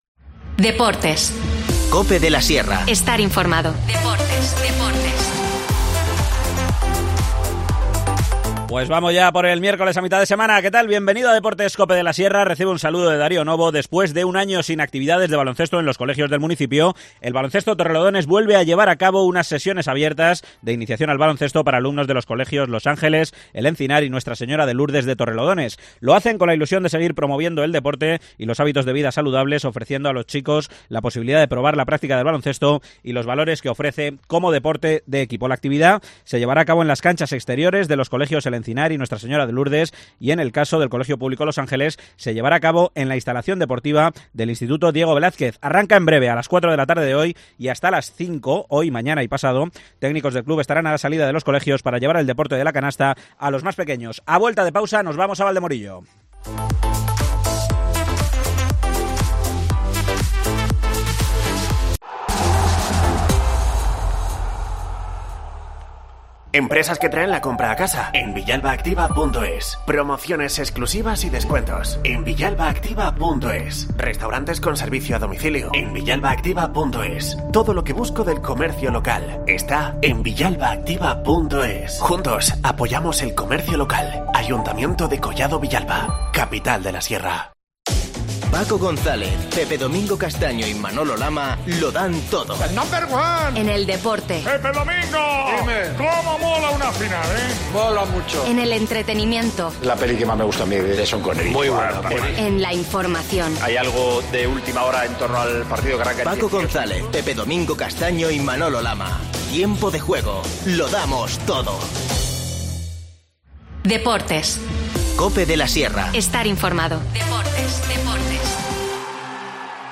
AUDIO: El próximo 12 de junio llega a Valdemorillo la I Milla Urbana. Nos lo cuenta su concejal de Deportes, Miguel Partida.